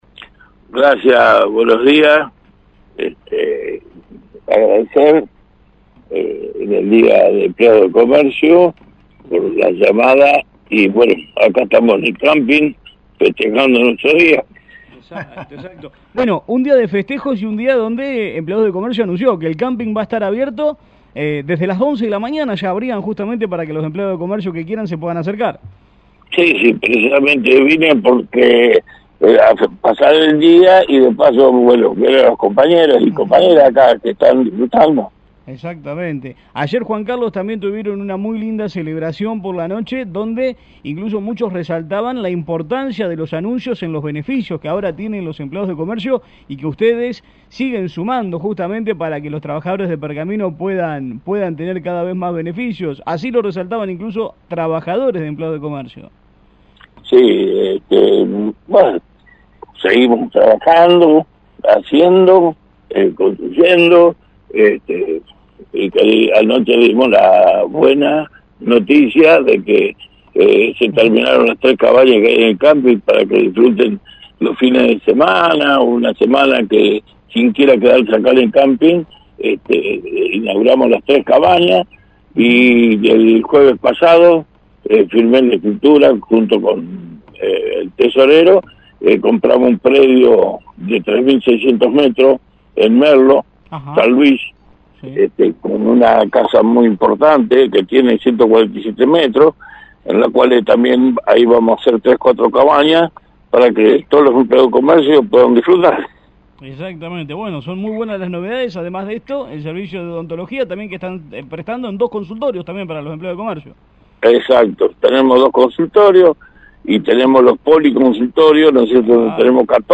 dialogó con el móvil de «La Mañana de la Radio» desde el Camping de Empleados de Comercio, mientras los trabajadores del rubro festejan su día.